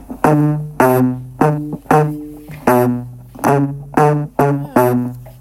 Banjo.mp3